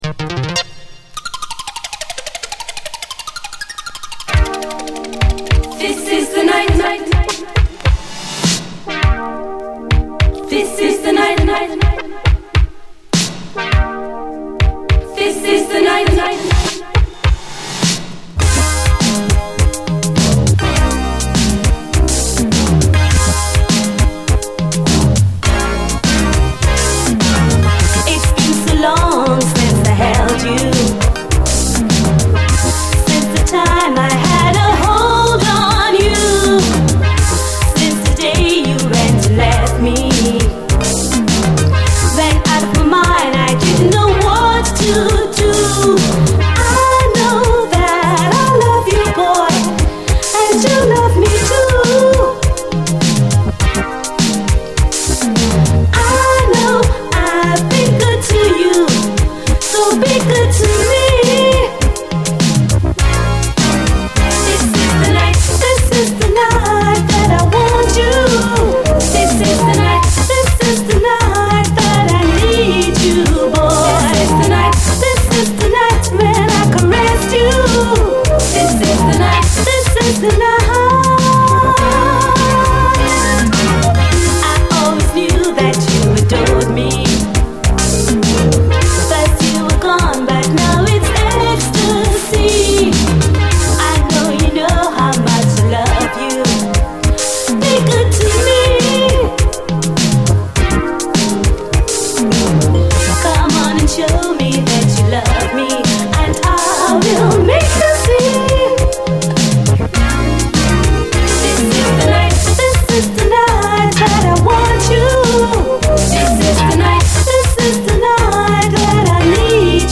SOUL, 70's～ SOUL, DISCO
妖しげでスペイシーなシンセ・サウンド！